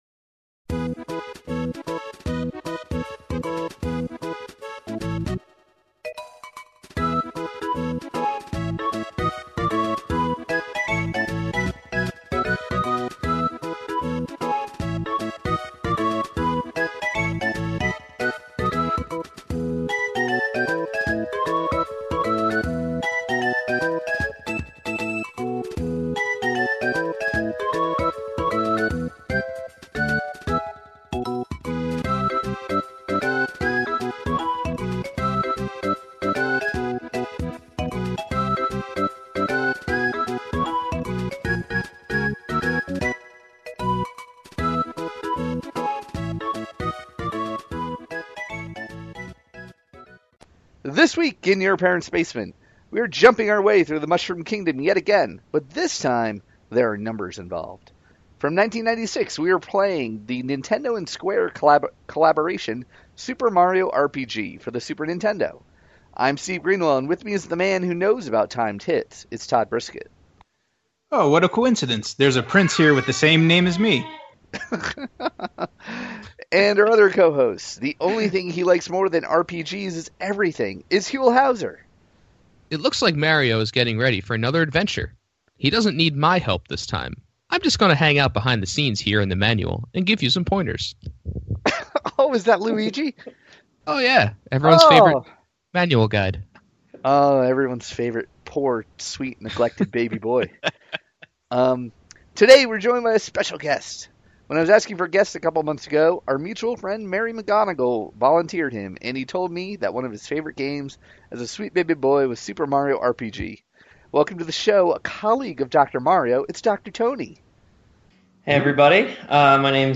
From 1988, we are playing Taito’s Bubble Bobble for the NES and Arcade. WARNING: This week’s show does not have the swears censored out!